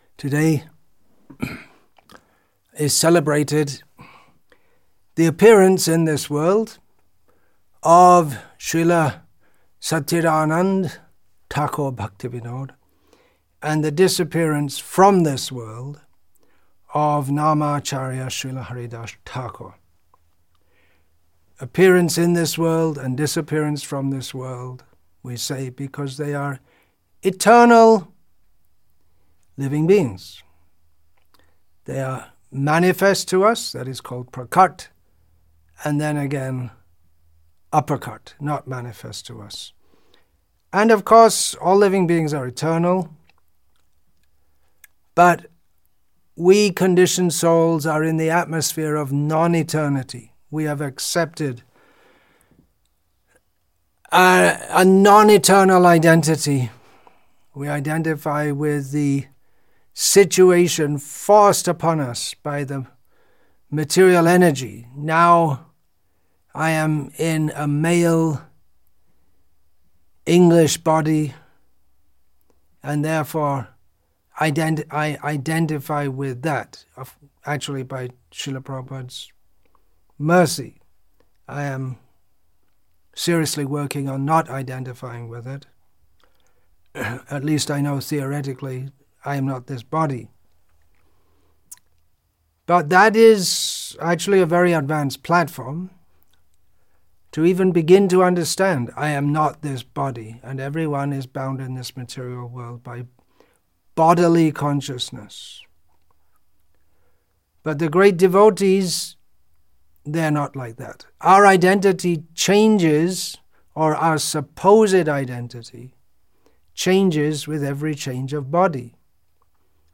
Assorted Lectures
Vellore, Tamil Nadu , India